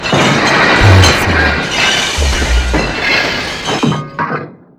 crashes.mp3